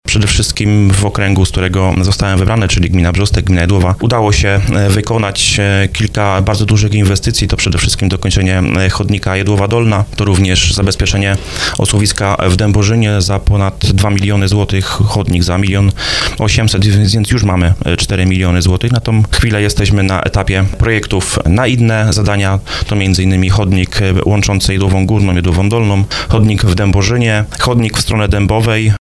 O zrealizowanych inwestycjach i planach na najbliższe miesiące mówił w programie 'Słowo za Słowo”.